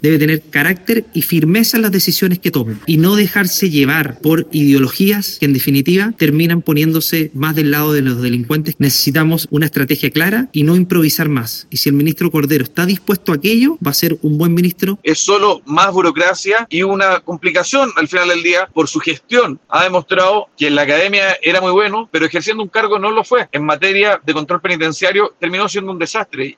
El diputado Andrés Longton (RN) indicó que Cordero debe mostrar firmeza en su estrategia. Por otro lado, el diputado y jefe de bancada Republicano, Cristian Araya, cuestionó el nombramiento de Cordero.